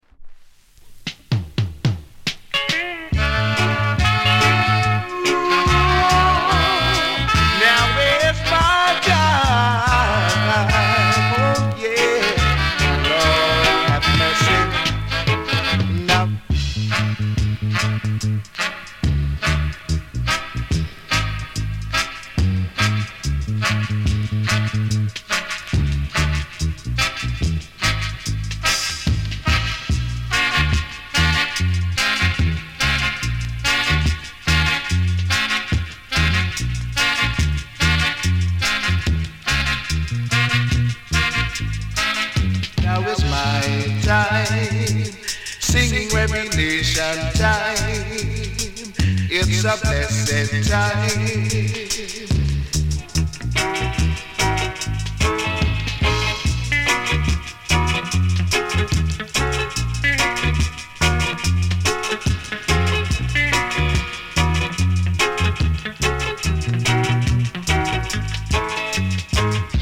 ジャマイカ盤 7inch/45s。
盤：EX-。大変良好です。薄いキズ or 擦れ 程度。大変キレイです！